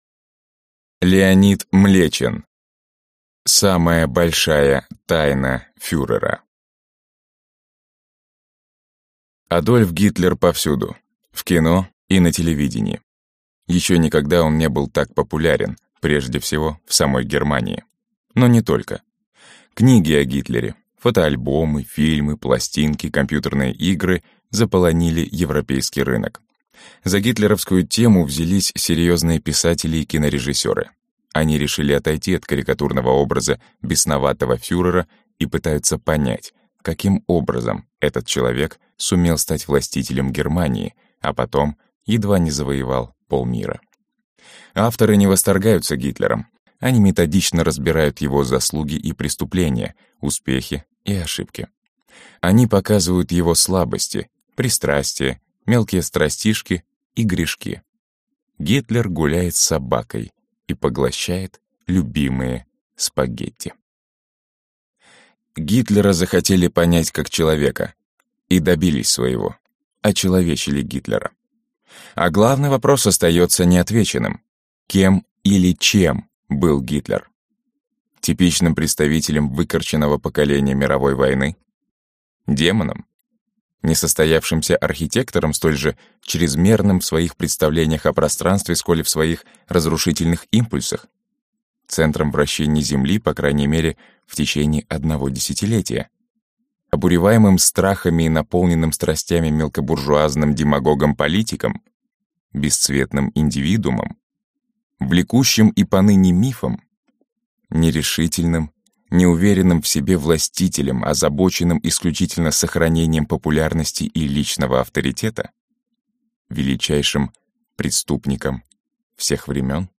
Аудиокнига Самая большая тайна фюрера | Библиотека аудиокниг